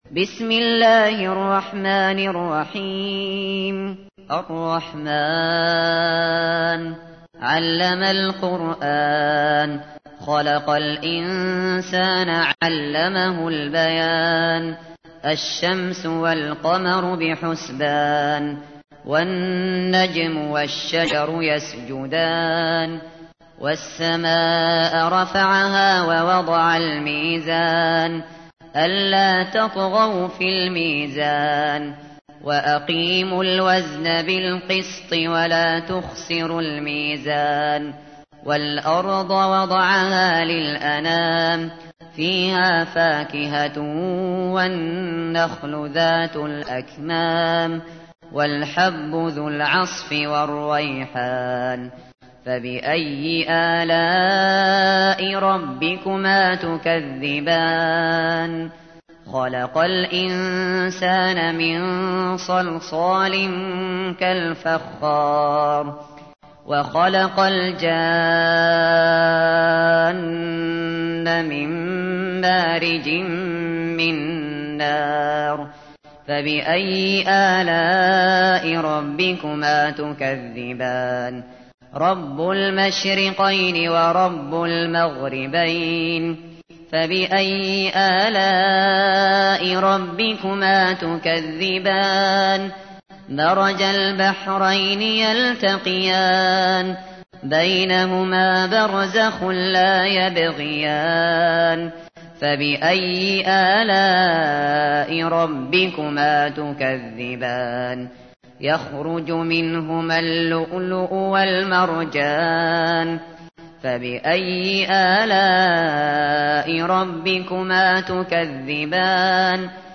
تحميل : 55. سورة الرحمن / القارئ الشاطري / القرآن الكريم / موقع يا حسين